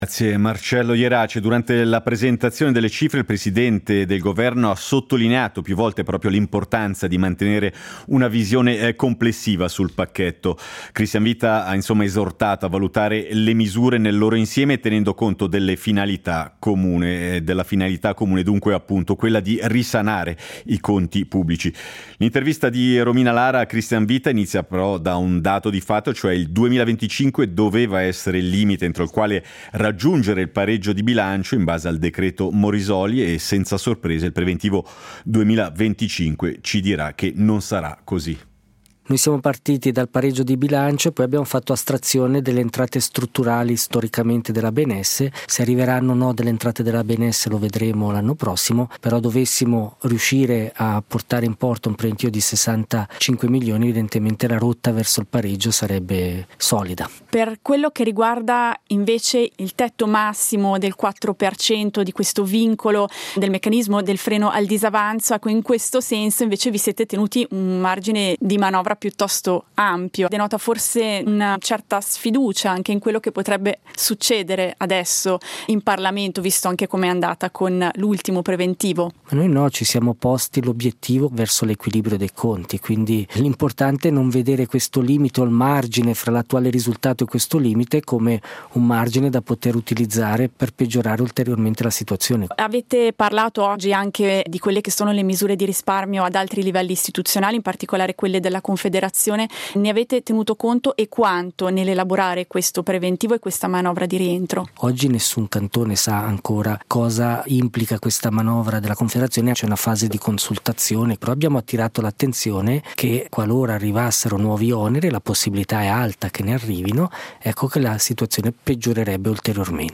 SEIDISERA del 25.09.2024: Preventivo: l'intervista a Christian Vitta